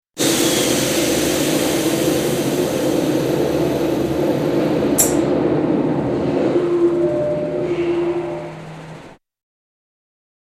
機械
削りだし（164KB）